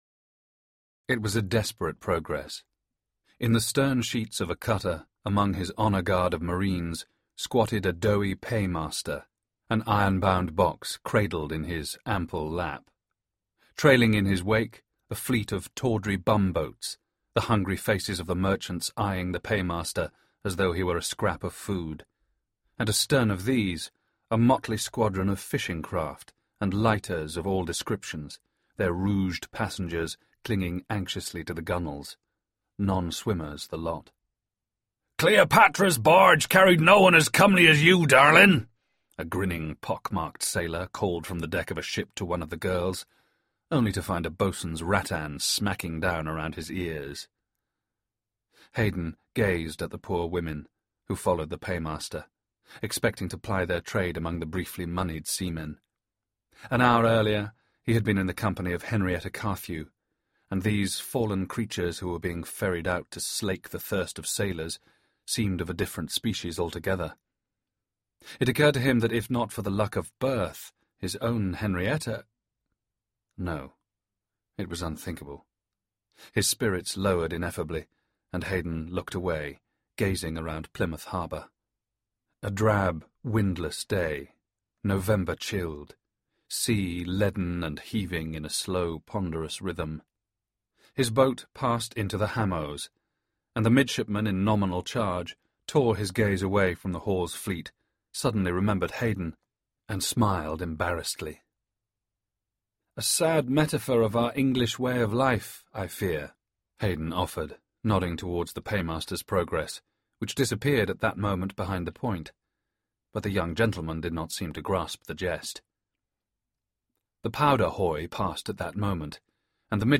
Four Minute MP3 Sample of the Audiobook for A Battle Won (2010) from Whole Story Audio Books
Audiobook FormatCD Unabridged